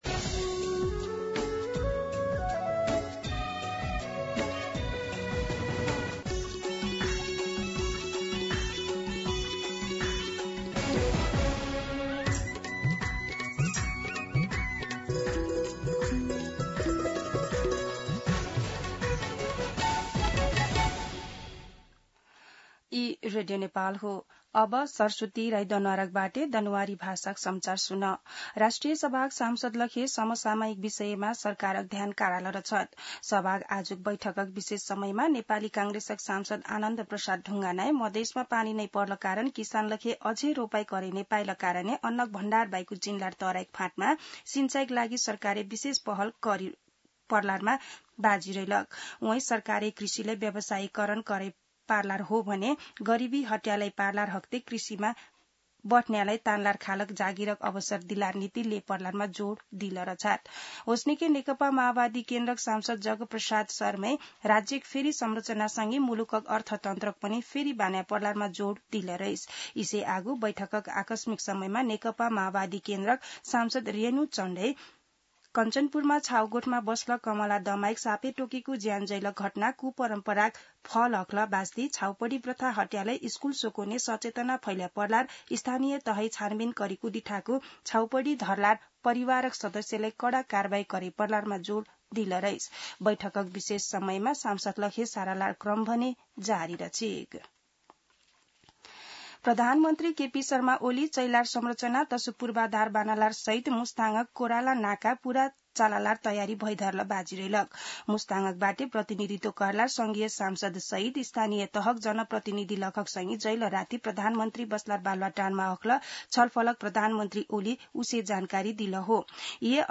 दनुवार भाषामा समाचार : ३१ असार , २०८२
Danuwar-News-31.mp3